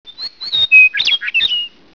I'LL SING FOR YOU-MEADOWLARK STATE BIRD.
meadowlark.wav